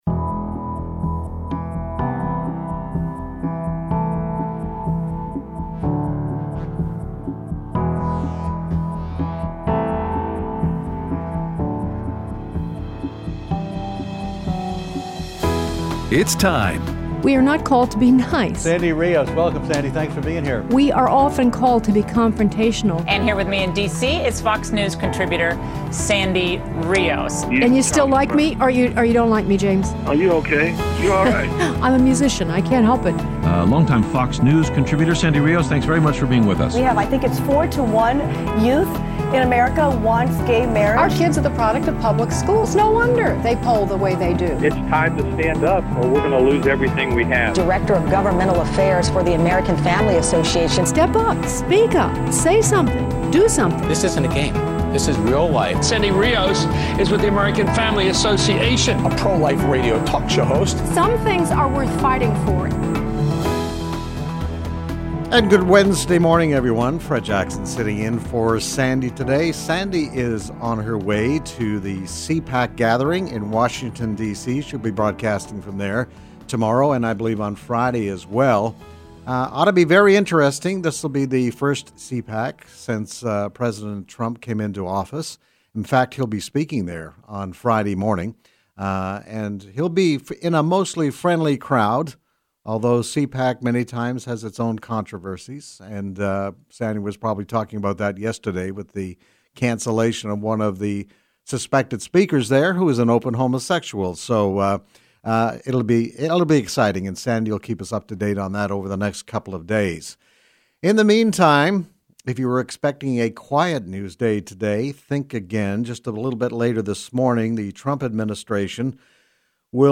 Aired Wednesday 2/22/17 on AFR 7:05AM - 8:00AM CST